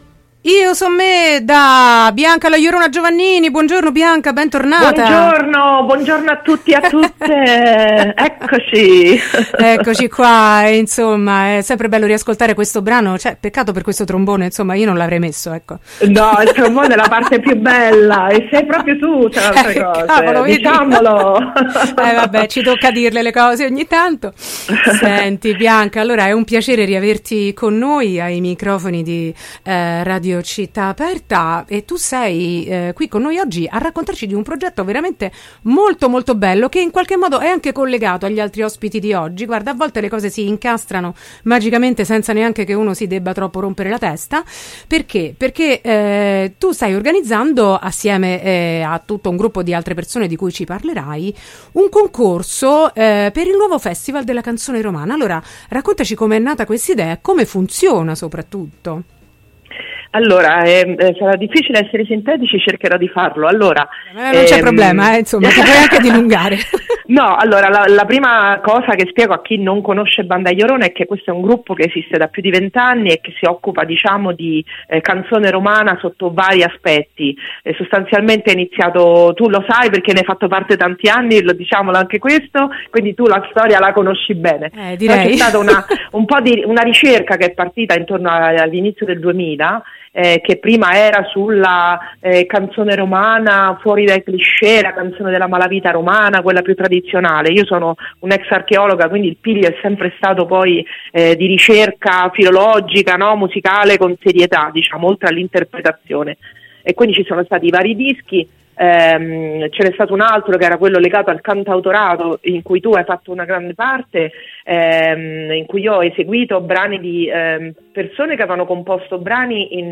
Arriva il concorso per il Nuovo Festival della Canzone Romana: intervista